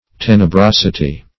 Search Result for " tenebrosity" : The Collaborative International Dictionary of English v.0.48: Tenebrosity \Ten`e*bros"i*ty\, n. The quality or state of being tenebrous; tenebrousness.